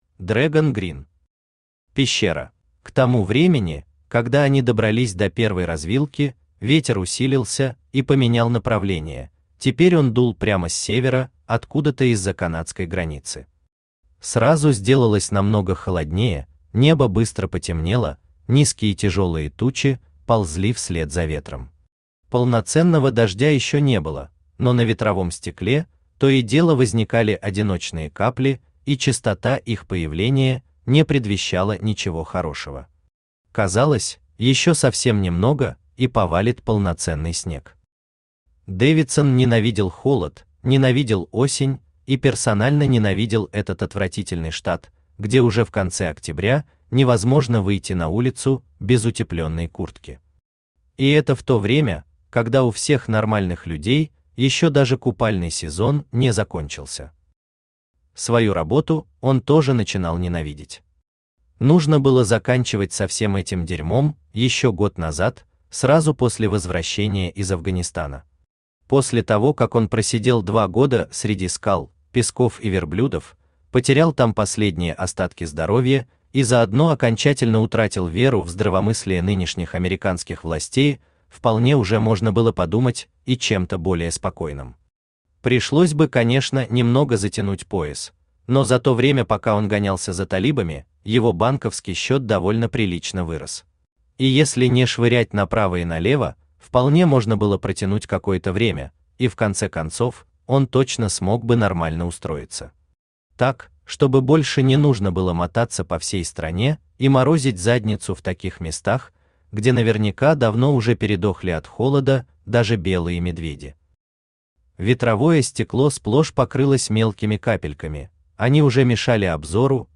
Аудиокнига Пещера | Библиотека аудиокниг
Aудиокнига Пещера Автор Dragon Green Читает аудиокнигу Авточтец ЛитРес.